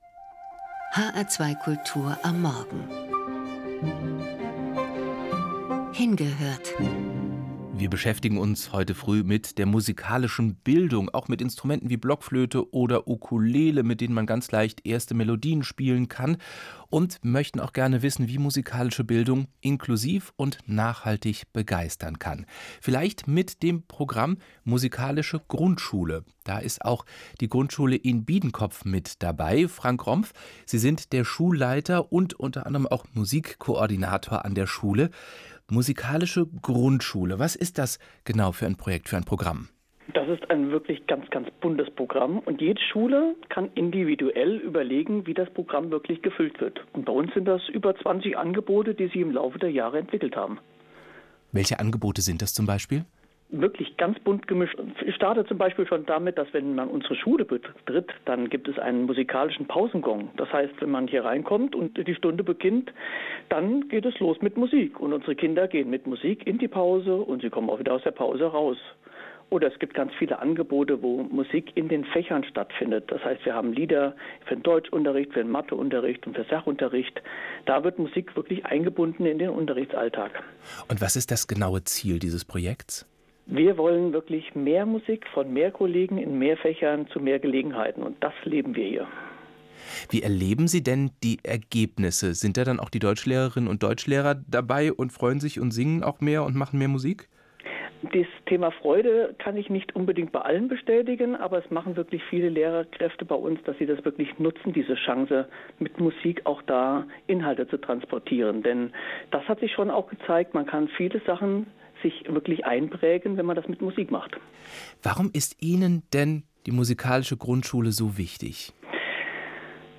Interview bei HR2 Kultur zur Musikalischen Grundschule
Interview-HR2Kultur-zu-MUGS.mp3